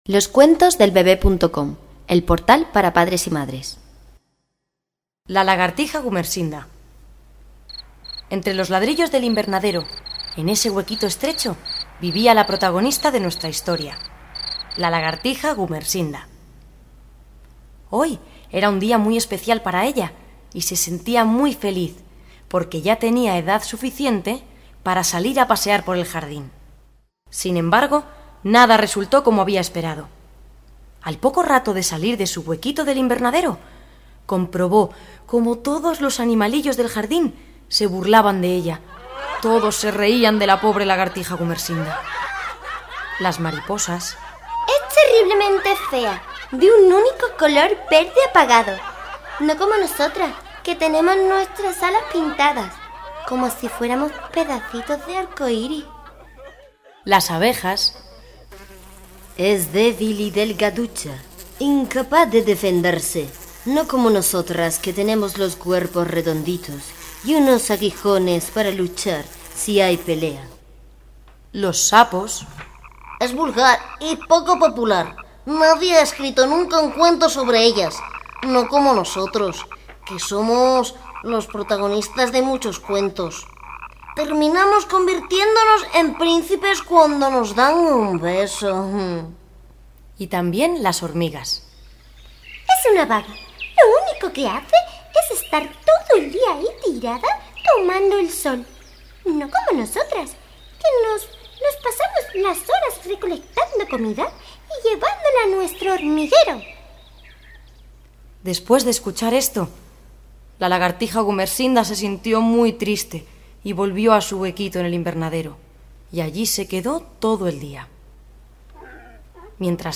Cuentos infantiles